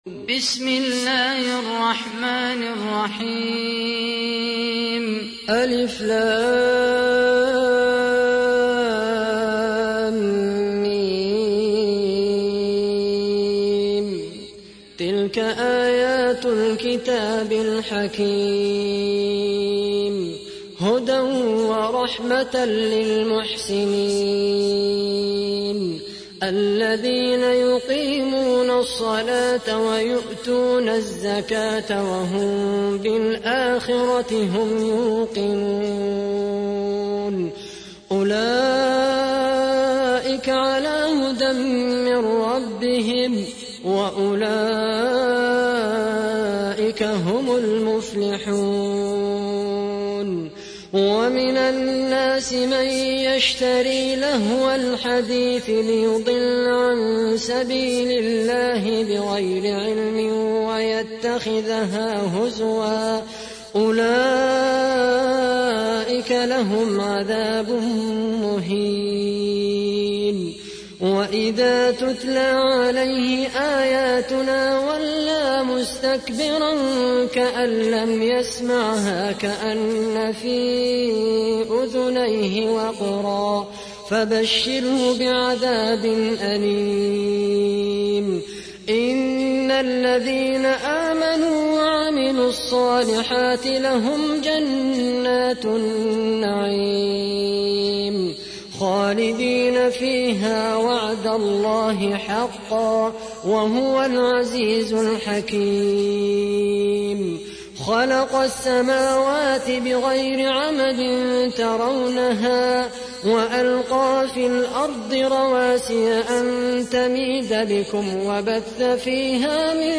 31. سورة لقمان / القارئ